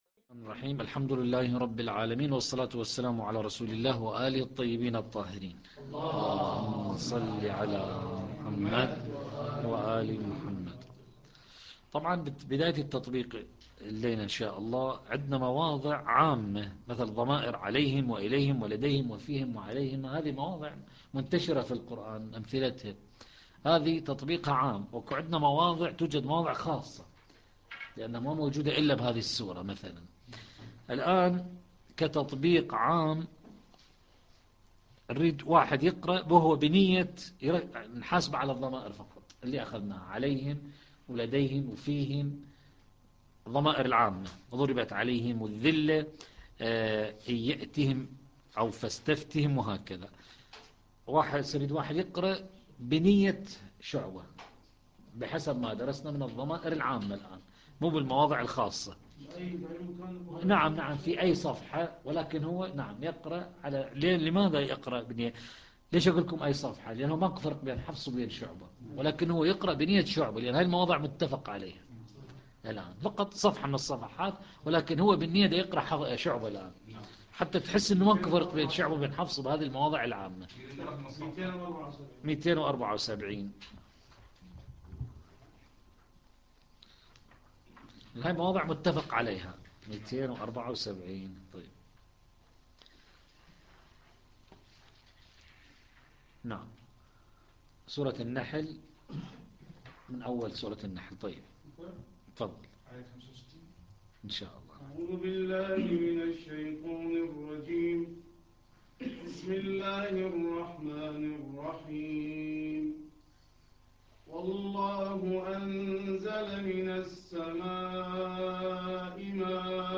الدرس الرابع - لحفظ الملف في مجلد خاص اضغط بالزر الأيمن هنا ثم اختر (حفظ الهدف باسم - Save Target As) واختر المكان المناسب